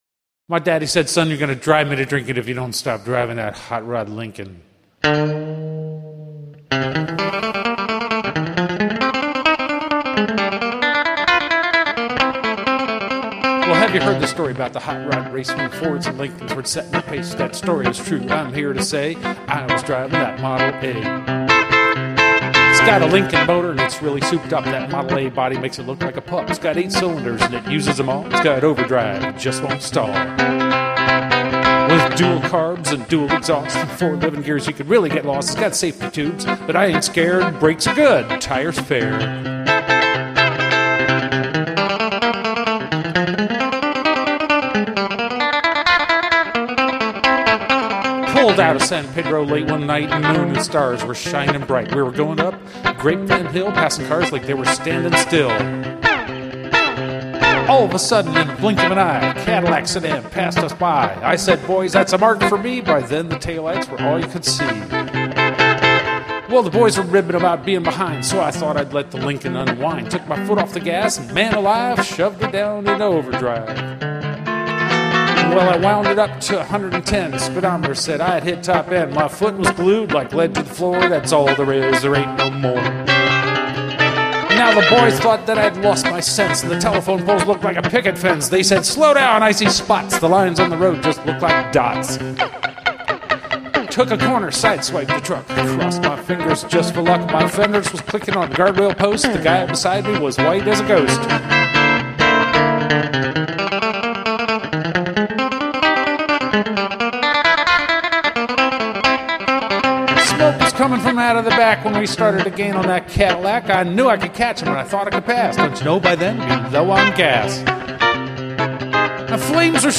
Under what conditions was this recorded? These are all single-take